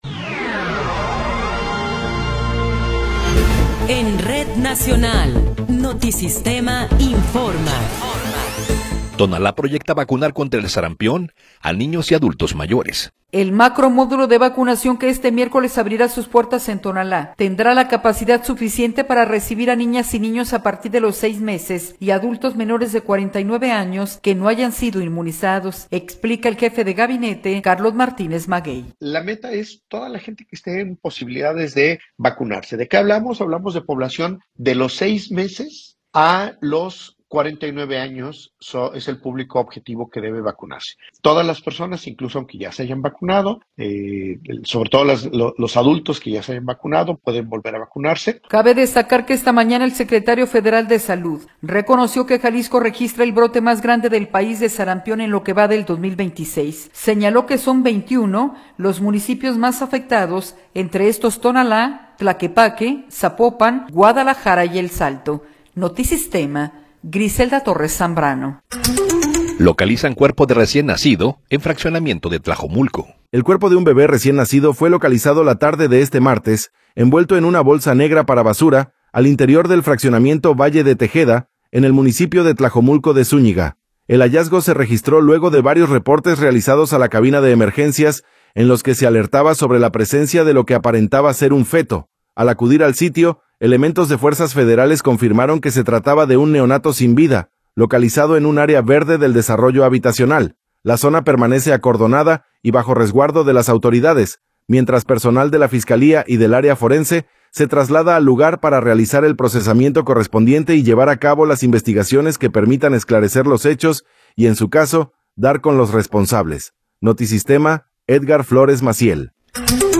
Noticiero 16 hrs. – 20 de Enero de 2026